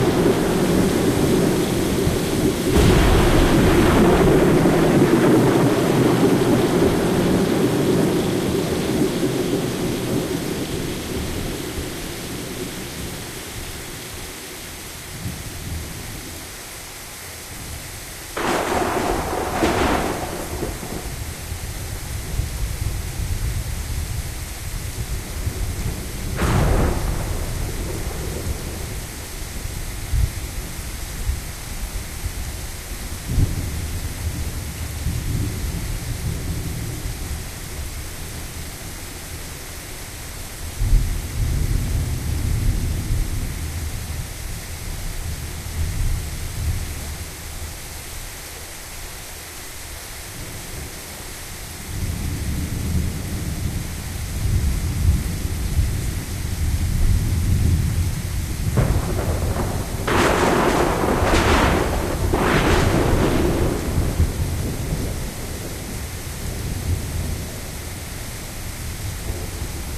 Storm2.ogg